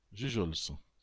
Jujols (French pronunciation: [ʒyʒɔls]